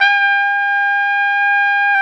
Index of /90_sSampleCDs/Roland L-CD702/VOL-2/BRS_Tpt 5-7 Solo/BRS_Tp 5 RCA Jaz